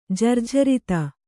♪ jarjharita